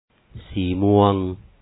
sǐi-múaŋ Purple